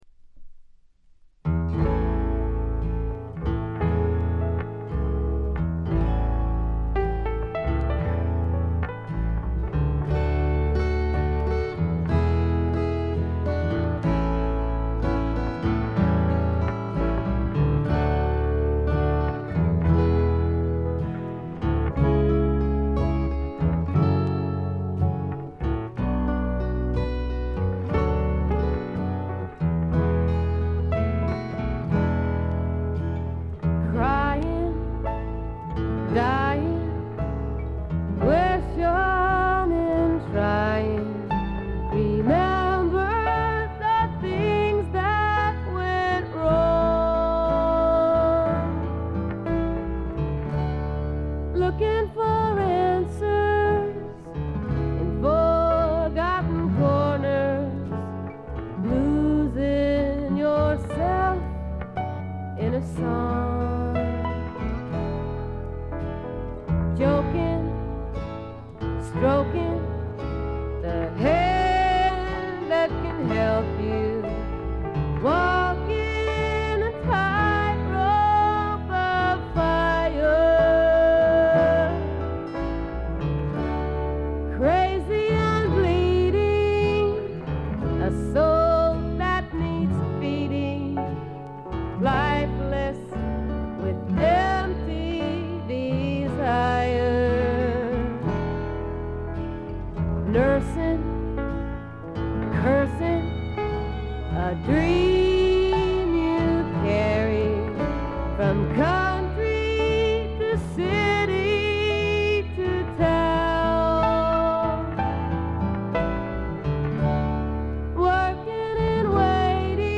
軽微なチリプチ少々。
フォーキーな女性シンガーソングライター作品の大名盤です！
試聴曲は現品からの取り込み音源です。